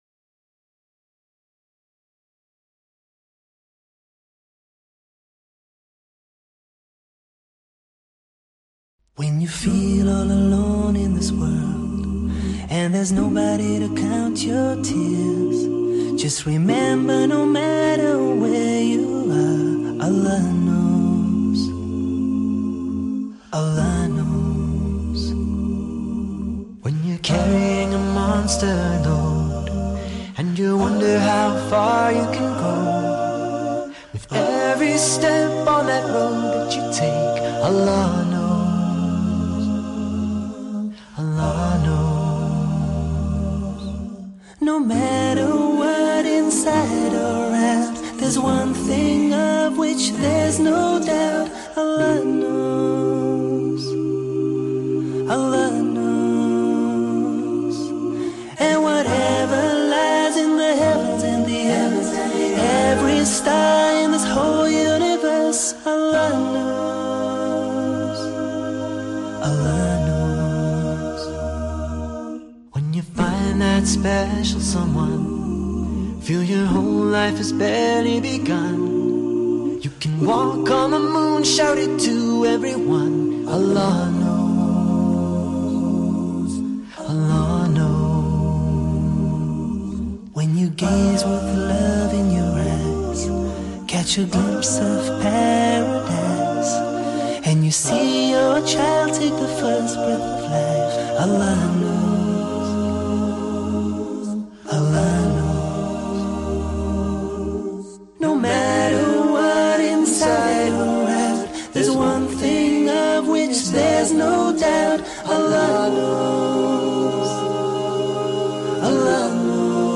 Allah Knows – Nasheed